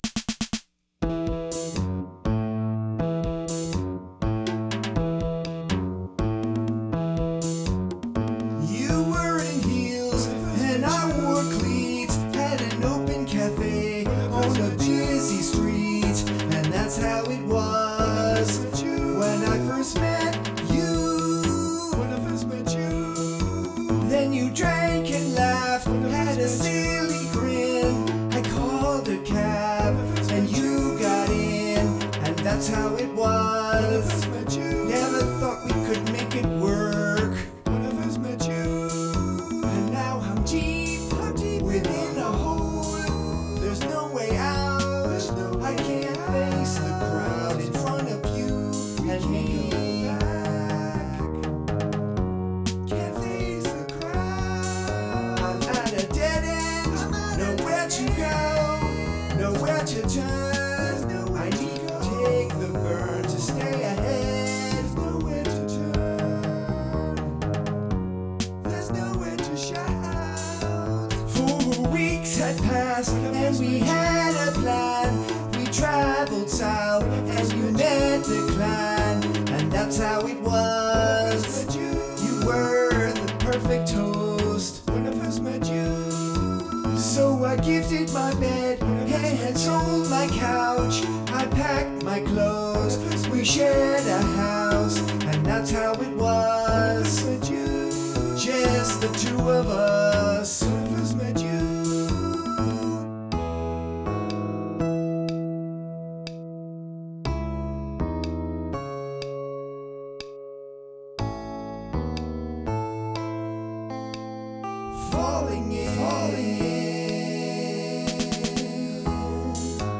guitar, bass guitars
Music only; no visuals, no video